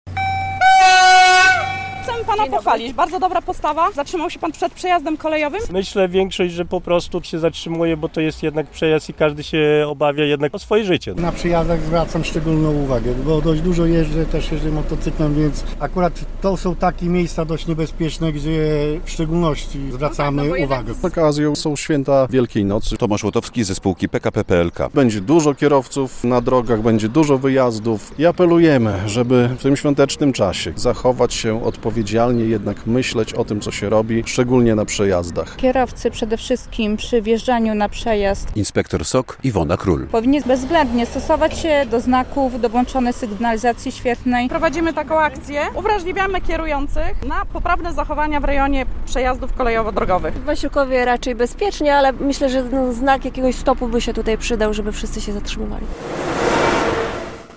O zasadach bezpiecznego podróżowania na przejazdach przypominali w czwartek (17.04) w Wasilkowie kolejarze, funkcjonariusze Służby Ochrony Kolei i policjanci.